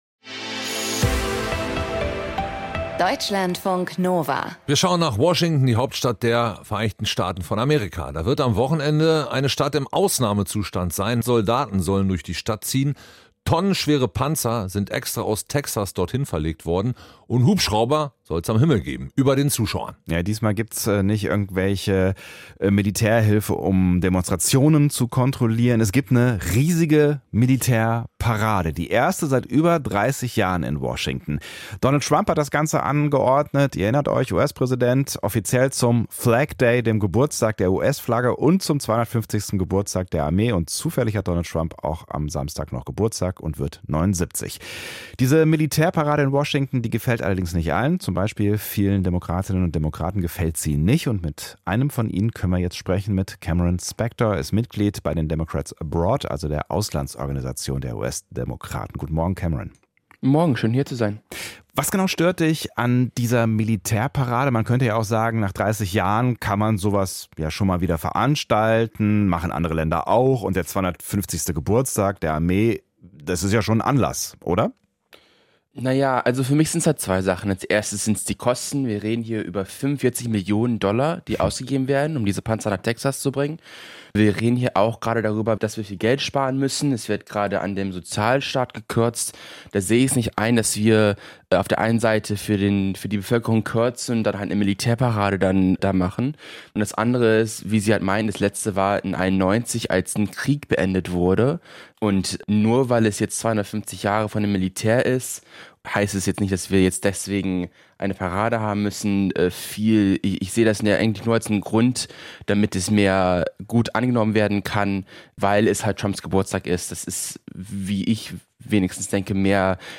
Das Interview im Deutschlandfunk Kultur greift kulturelle und politische Trends ebenso auf wie...